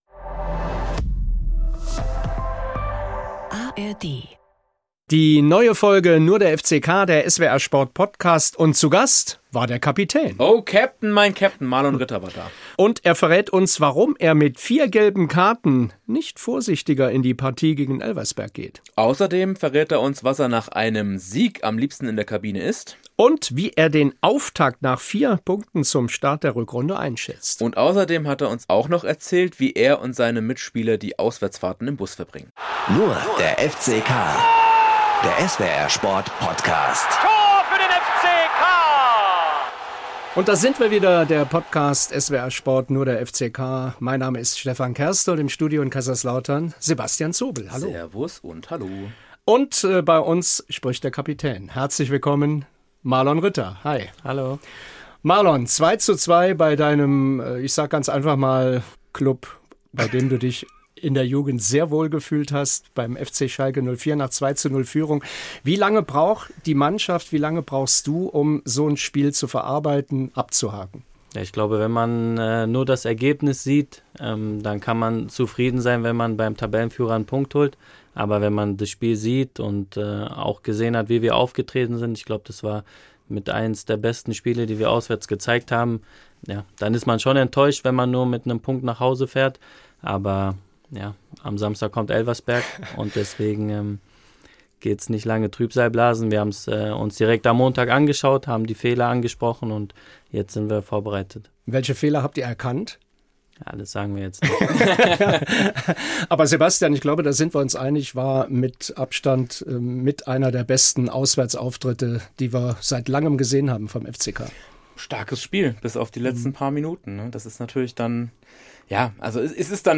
Der FCK-Kapitän spricht im "Nur der FCK"-Podcast über das 2:2 bei Tabellenführer Schalke, klare Learnings – und warum bei ihm nach Feierabend wirklich Feierabend ist.